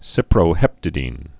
(sīprō-hĕptə-dēn)